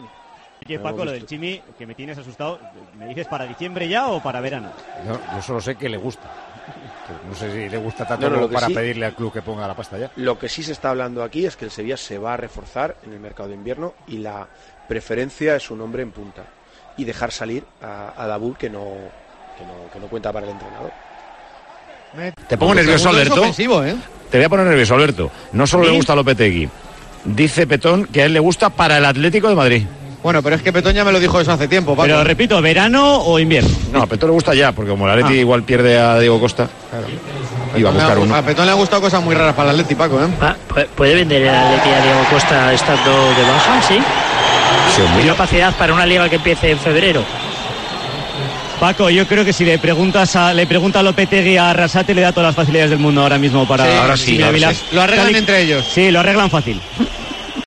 Durante la narración del partido Osasuna-Sevilla en Tiempo de Juego de la Cadena COPE, Paco González afirmó que al entrenador del Sevilla, Julen Lopetegui, "le gusta el Chimy Ávila".
En el audio se bromea que tras la polémica entre los dos entrenadores era "un buen momento" para abordar el fichaje.